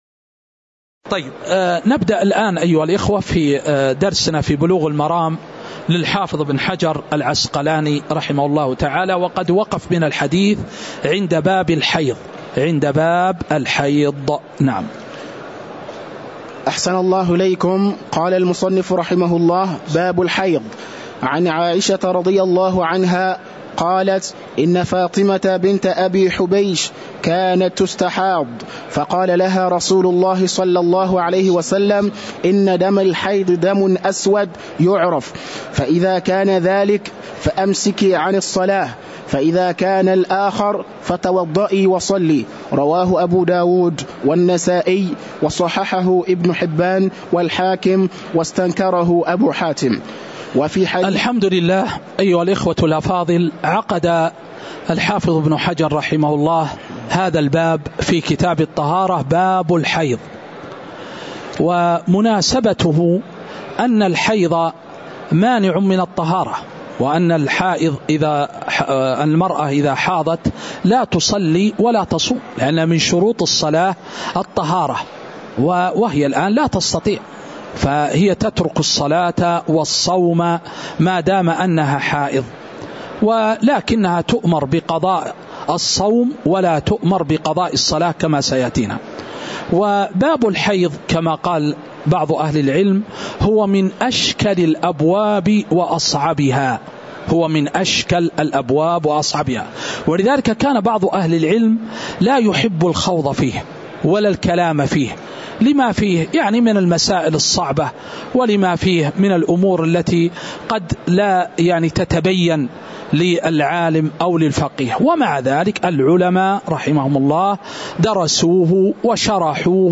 تاريخ النشر ٢٩ ذو الحجة ١٤٤٤ هـ المكان: المسجد النبوي الشيخ